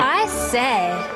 The ad also contains a beautiful parody of the [ɪ] ending of RP’s diphthongs, when this actress produces the old-fashioned exclamation I say!